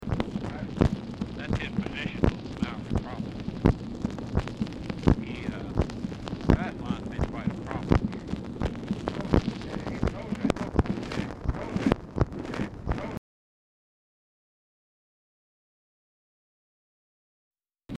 Telephone conversation
VERY POOR SOUND QUALITY; RECORDING STARTS AFTER CONVERSATION HAS BEGUN
Dictation belt